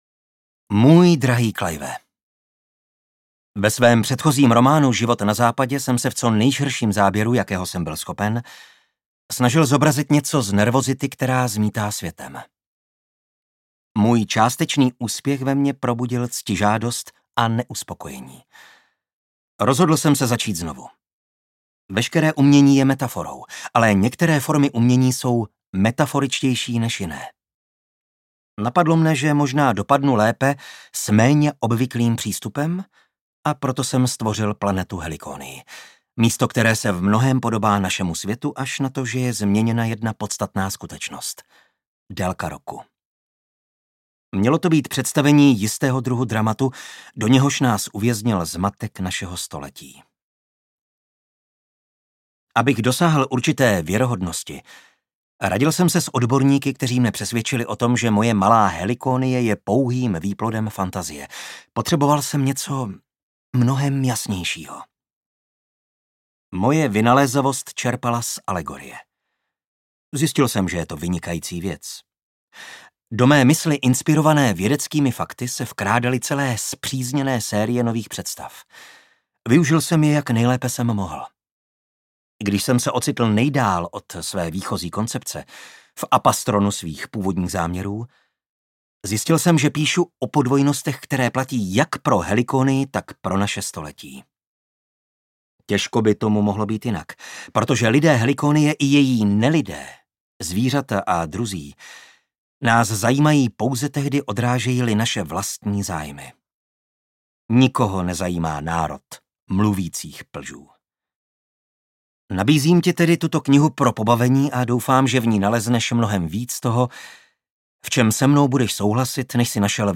UKÁZKA Z KNIHY
audiokniha_helikonie_jaro_ukazka.mp3